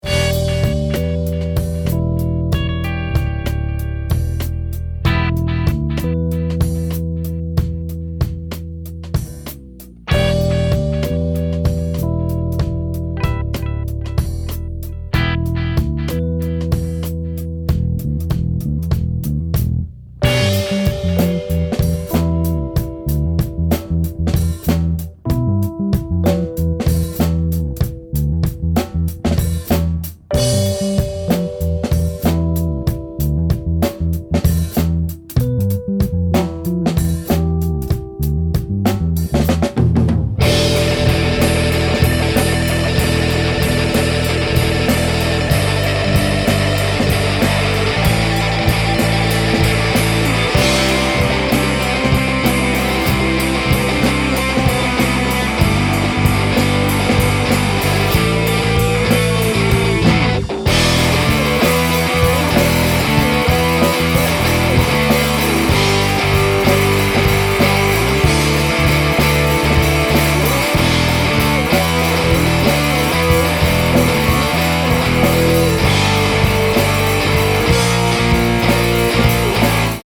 Пример сведения рок-композиции Категория: Написание музыки
Пример сведения рок-композиции (написано, записано и сведено мной лично в студии London School of Sound)